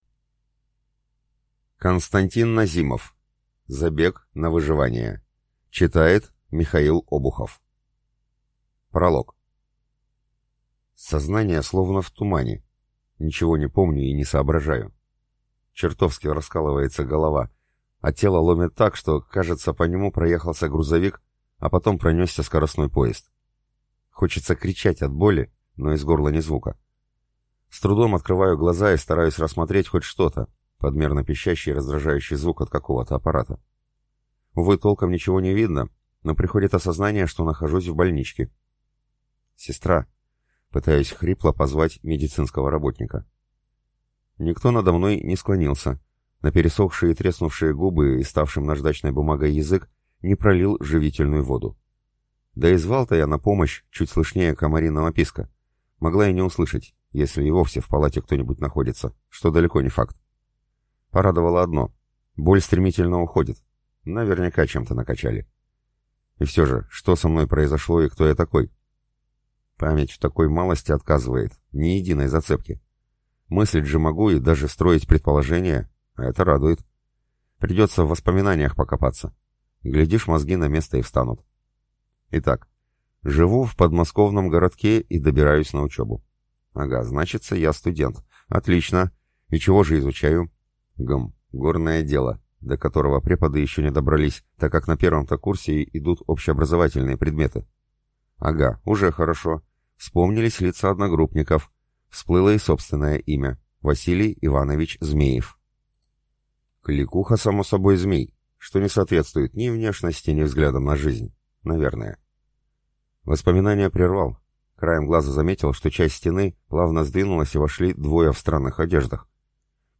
Аудиокнига Забег на выживание | Библиотека аудиокниг